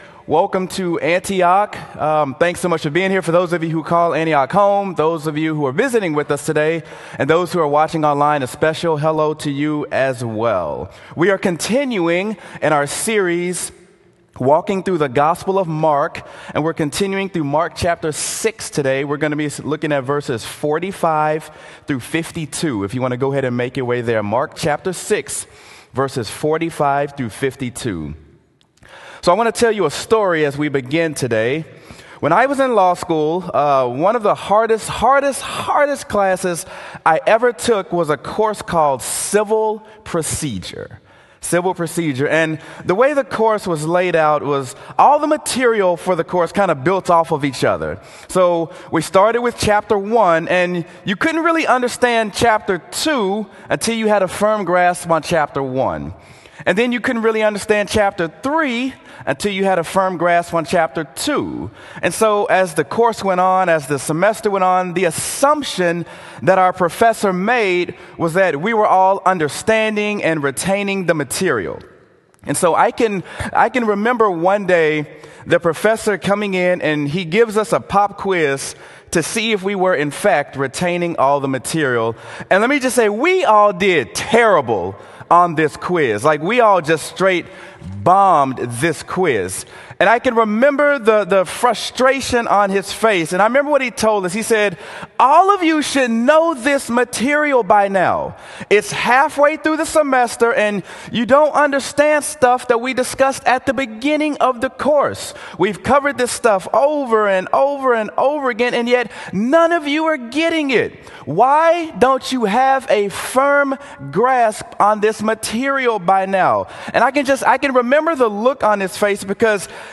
Sermon: Mark: Don’t Have a Hard Heart
sermon-mark-dont-have-a-hard-heart.m4a